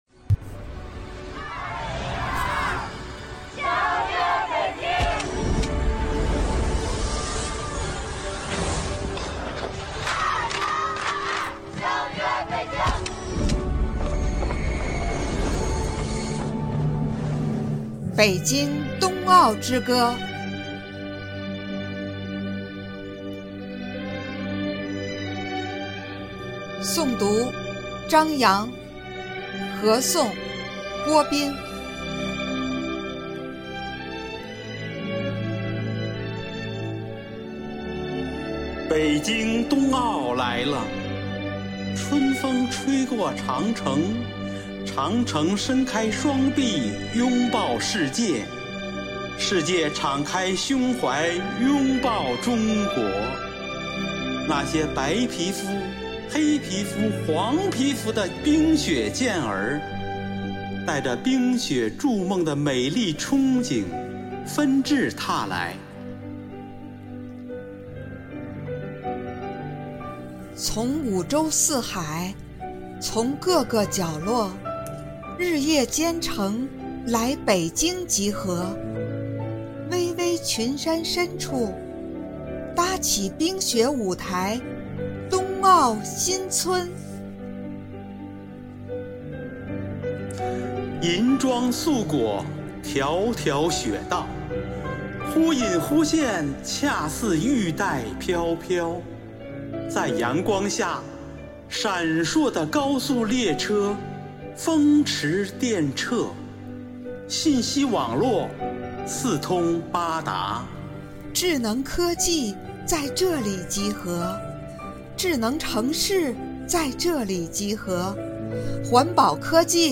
以诗为言，以声传情，用诗文朗诵抒发了对过去一整年的留恋与不舍以及对新年的憧憬与期盼，真情展现了生活好课堂朗读志愿者的朗读风采，队员们彼此感情更加深切。
生活好课堂幸福志愿者诵读心声朗读服务（支）队